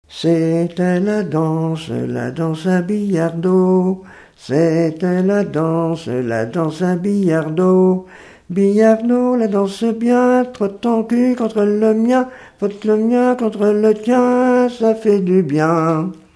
Chants brefs - A danser
Pièce musicale inédite